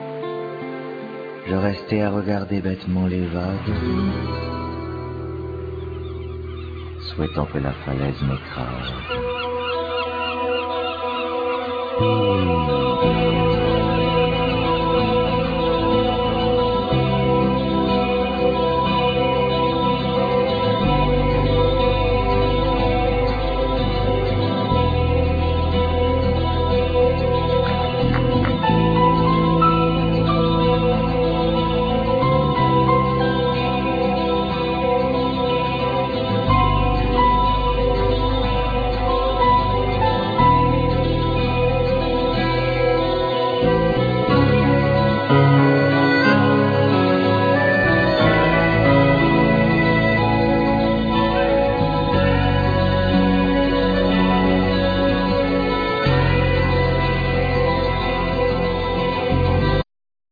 Harp
Bamboo Flute